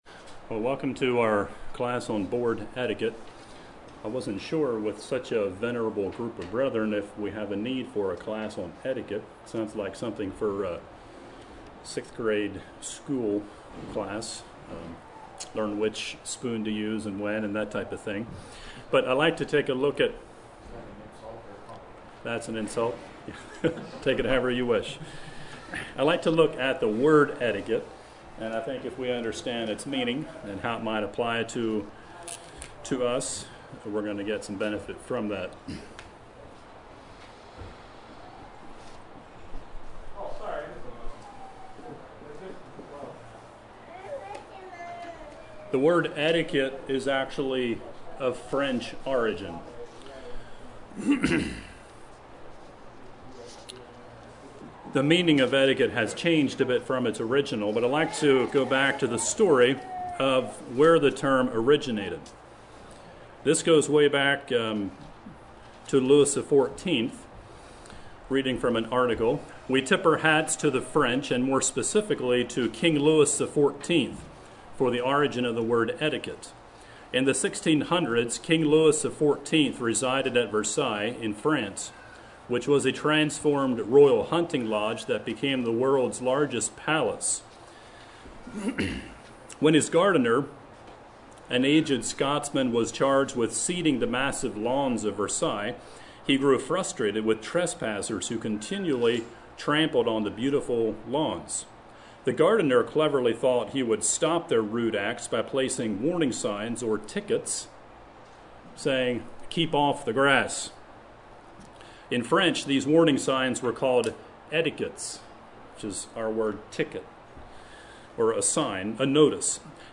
Home » Lectures » Board Etiquette